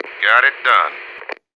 marine_order_complete1.wav